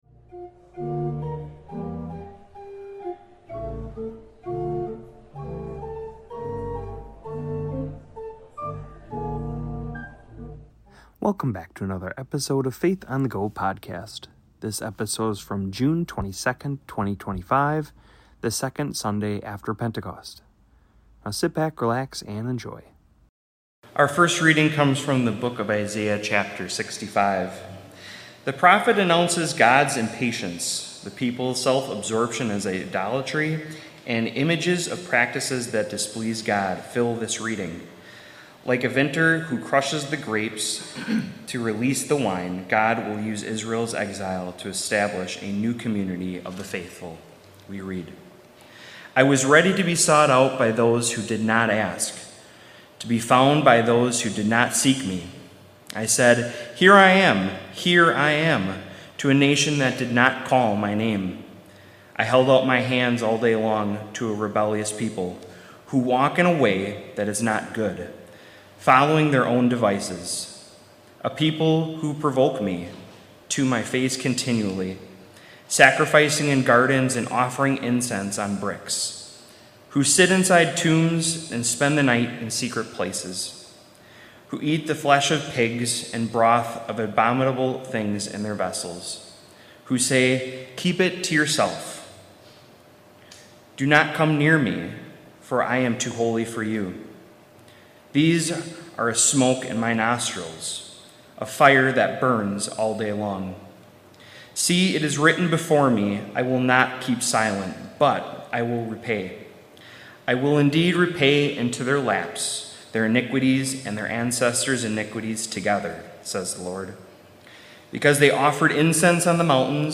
Faith on the Go podcast is a recap of the re-readings, gospel, and sermon